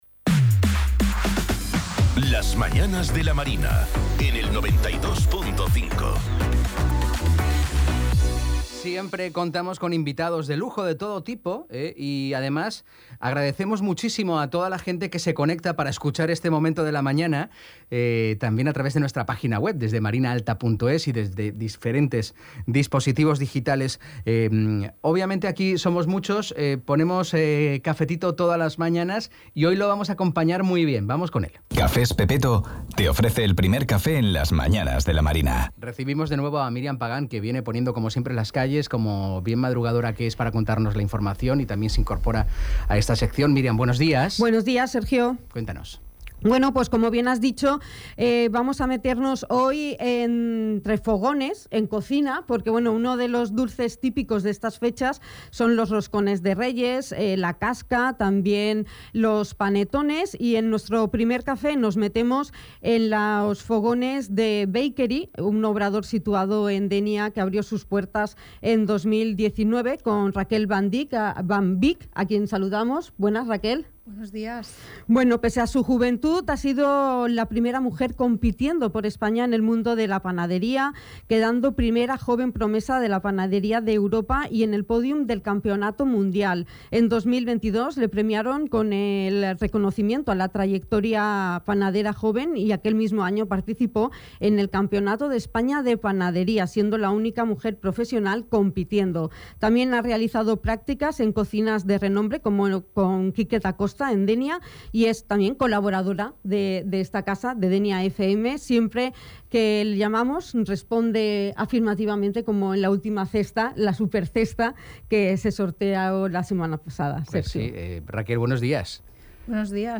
Entrevista-Beekery-1.mp3